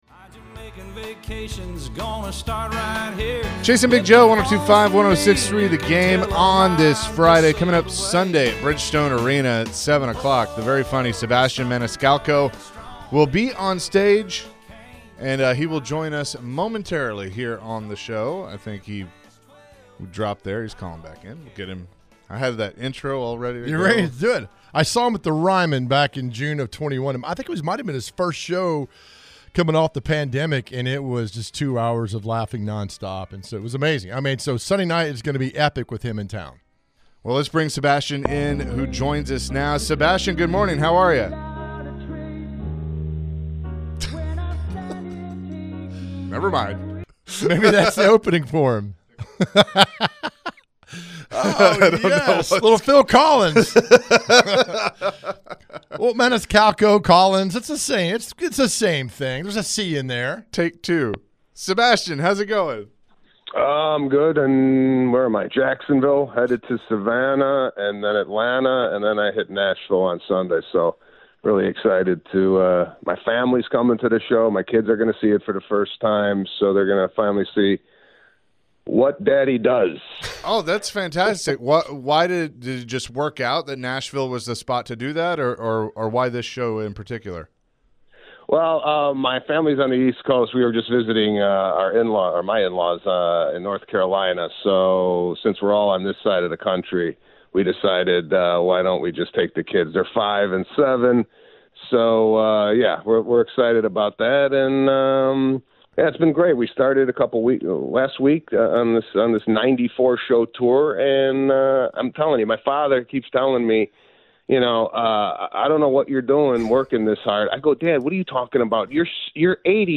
Sebastian mentioned his upbringing in comedy and how his comedy bits are so relatable to his fans. Listen to the conversation and share some laughs.